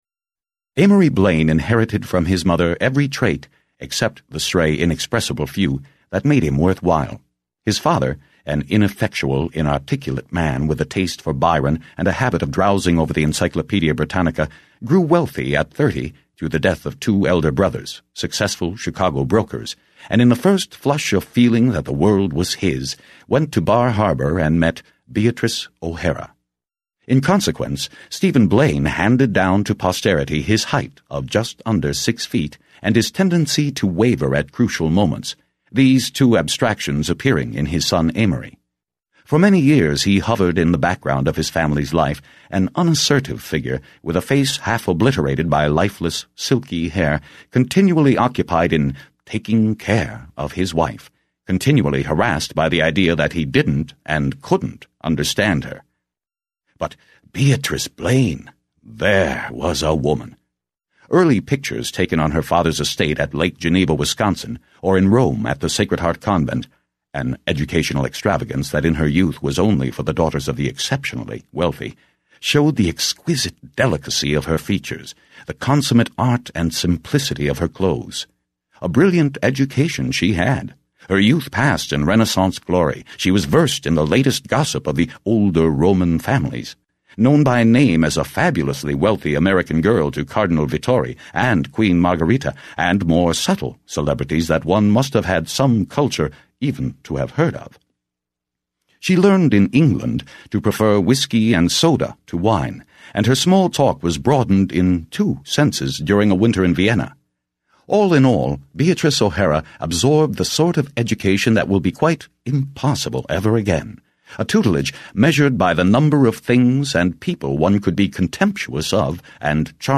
Englische Originalfassung Vollständige Lesung plus PDF-Textdatei Sprecher